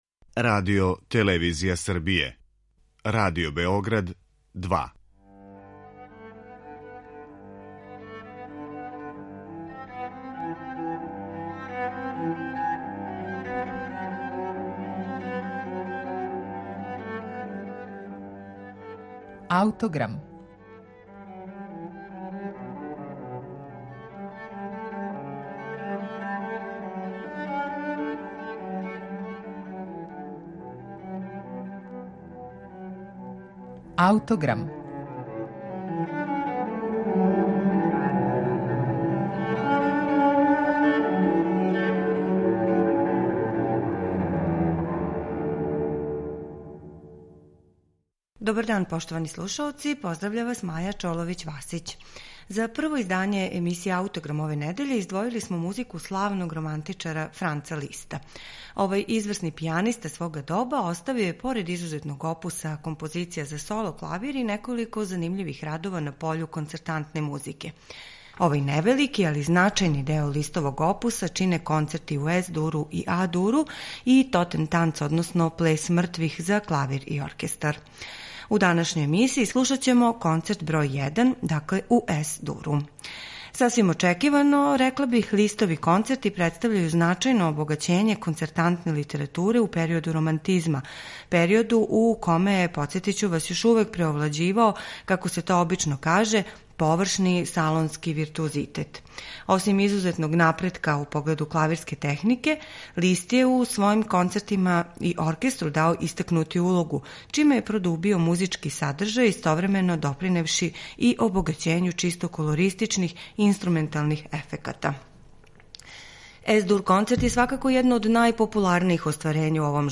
Овај концерт се сврстава у највеће изазове за савремене пијанисте, а једну од најупечатљивијих интерпретација остварила је Марта Аргерич уз Лондонски симфонијски оркестар, под управом Клаудија Абада.